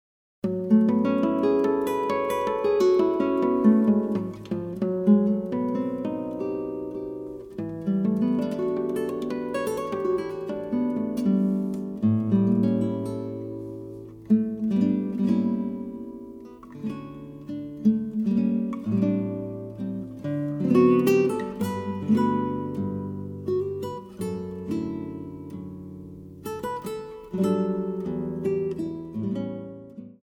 acoustic seven-string guitar
electric guitar, vocals
pedal steel guitar
acoustic bass
drums